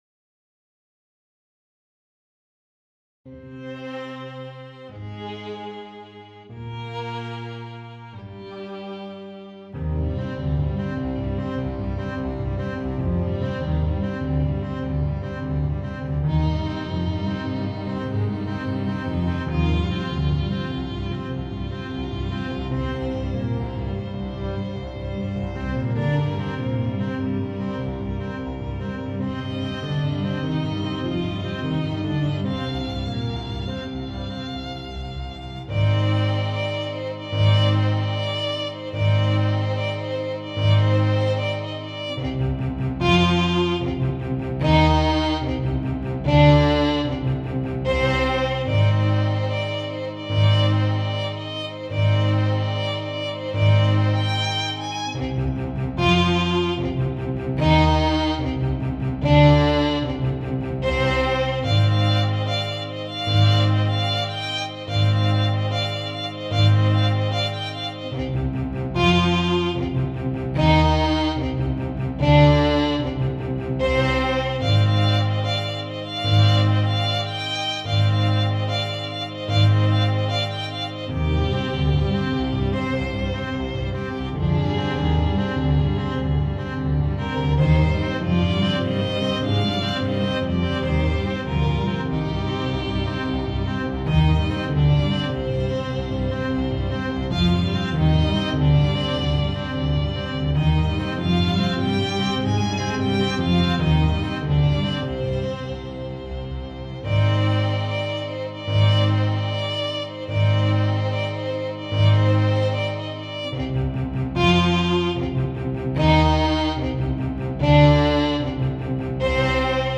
para Quarteto de Cordas e Piano
● Violino I
● Violino II
● Viola
● Violoncelo